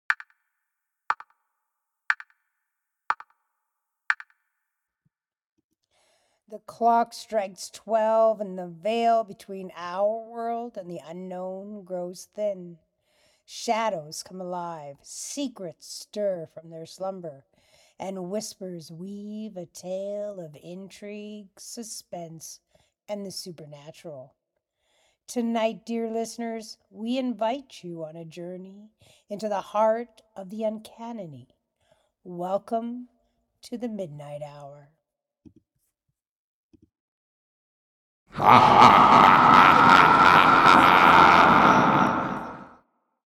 Recording 2: Once I listened to my voice I did not want the echo (reverb) and did another one with Clean sound
Clean-voice.mp3